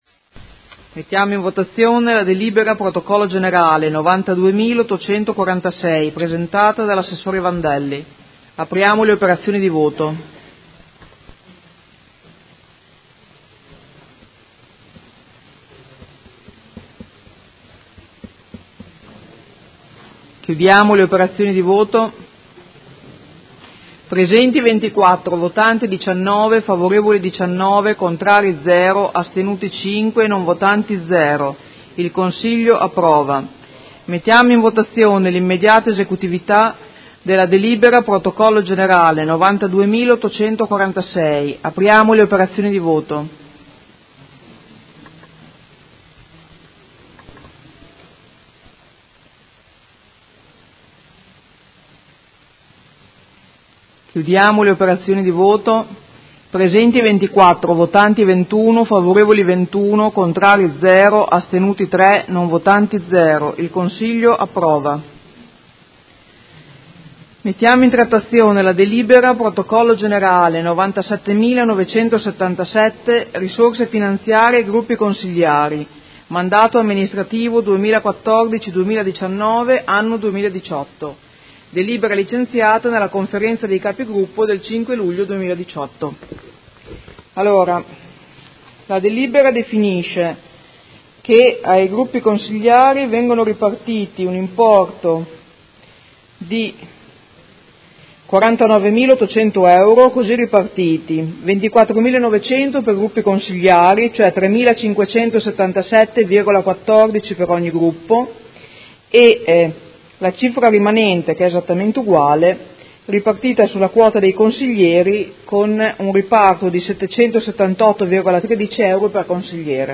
Presidentessa — Sito Audio Consiglio Comunale
Audio Consiglio Comunale